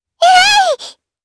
Lavril-Vox_Attack4_jp.wav